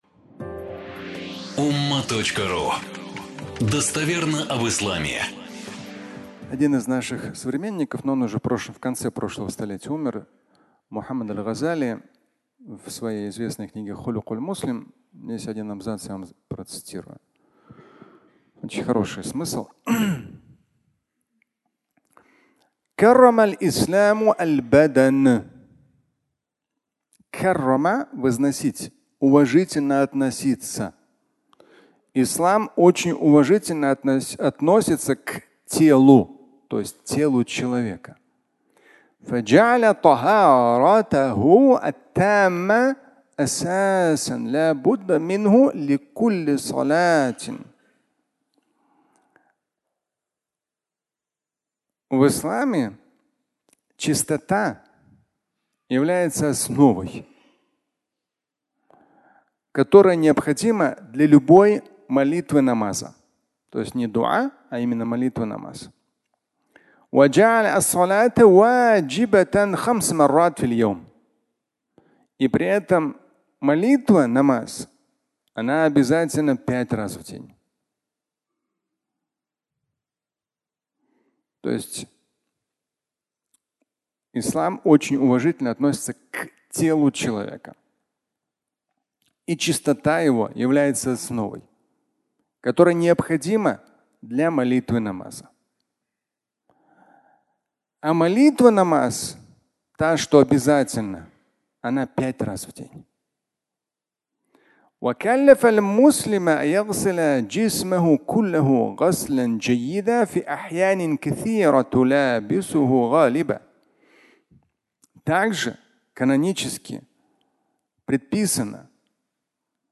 Любовь к себе (аудиолекция)